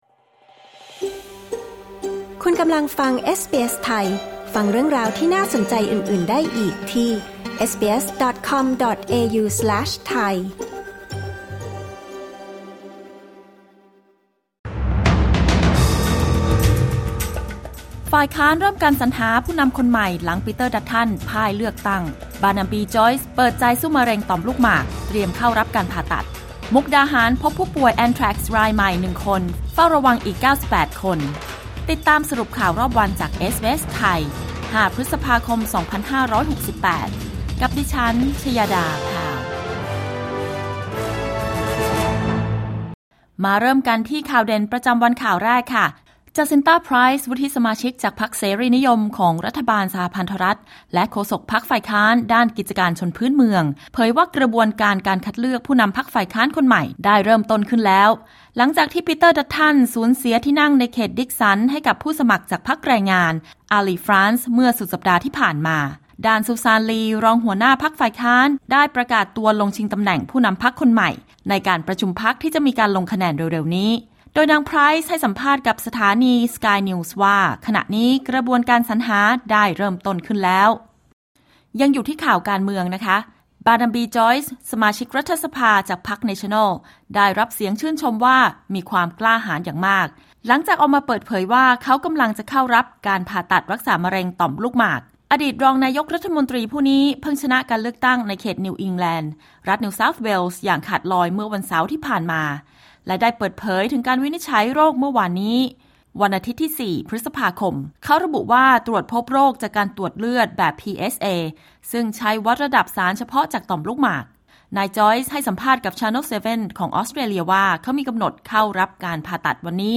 สรุปข่าวรอบวัน 5 พฤษภาคม 2568